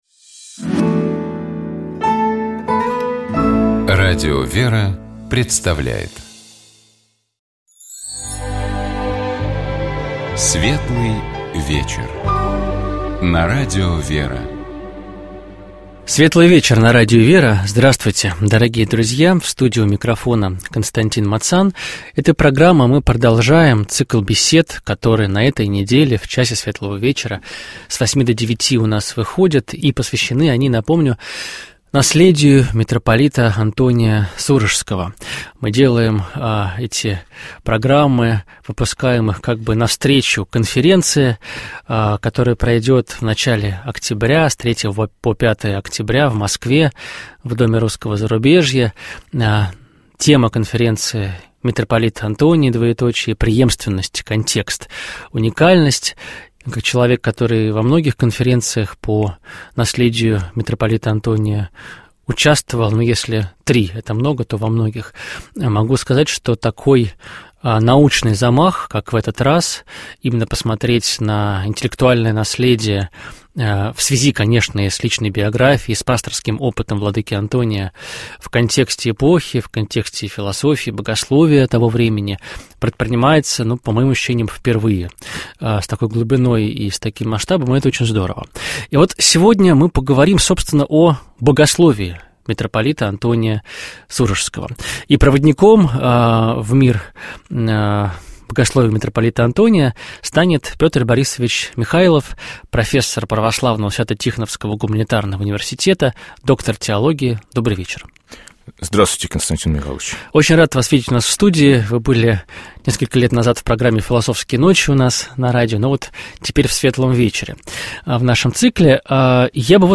Беседа из цикла про образование, который Радио ВЕРА организует совместно с образовательным проектом «Клевер Лаборатория», которая объединяет учителей, руководителей школ и детских садов, родителей и всех тех, кто работает с детьми и занимается их духовно-нравственным развитием.